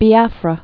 (bē-ăfrə, -äfrə)